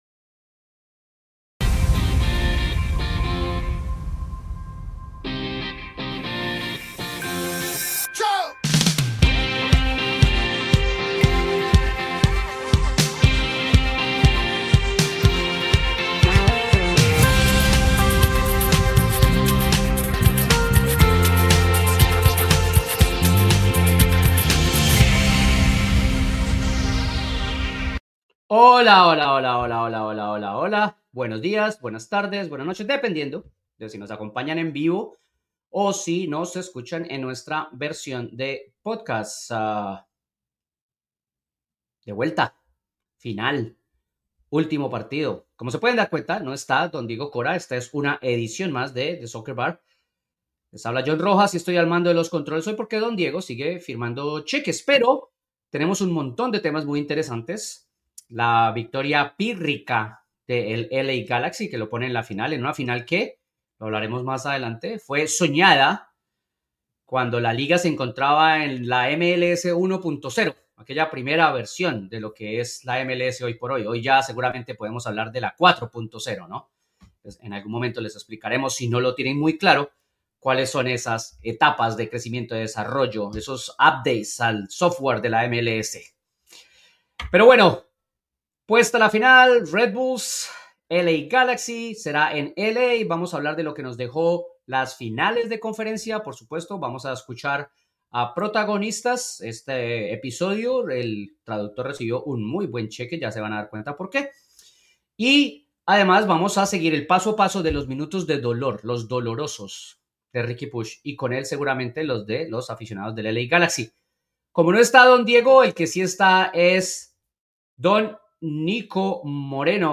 Repasamos lo sucedido en las finales de conferencia, la final ya puesta y las consecuencias de lo sucedido el fin de semana en MLS. Escuchamos protagonistas y analizamos.